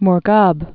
(mr-gäb)